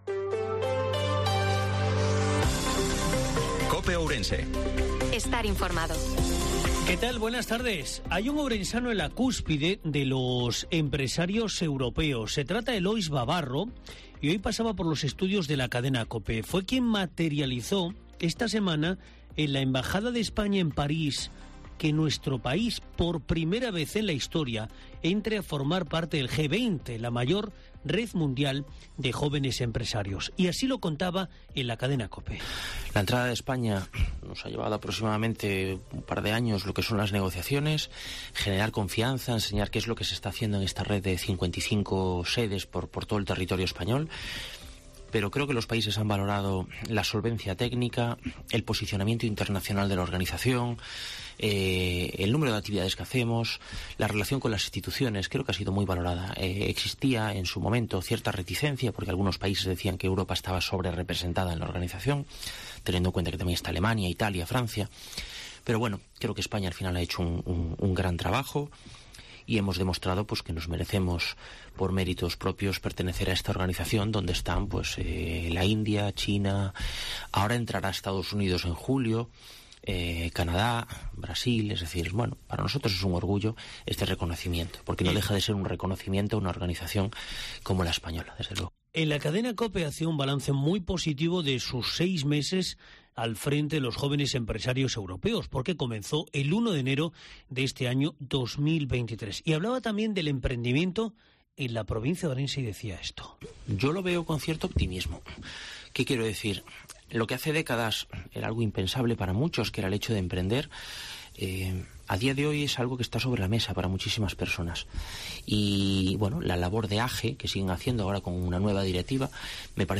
INFORMATIVO MEDIODIA COPE OURENSE-12/06/2023